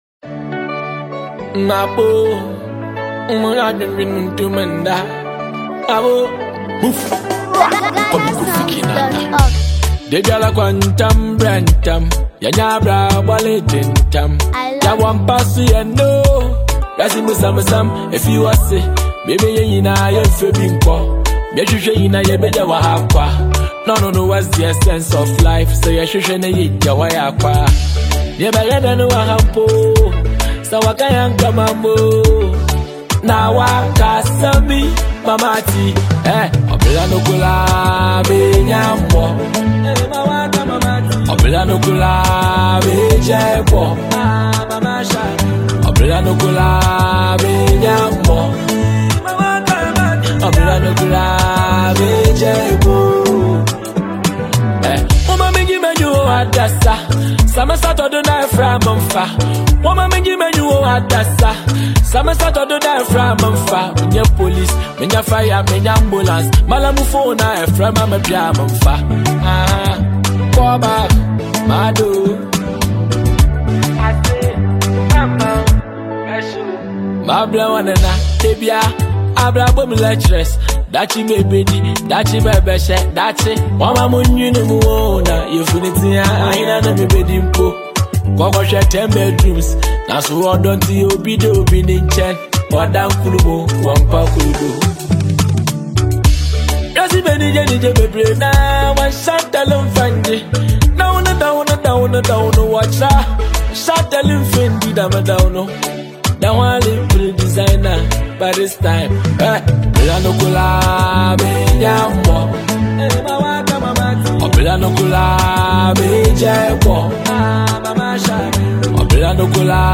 and smooth highlife-inspired sound.